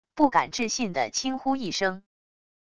不敢置信的轻呼一声wav音频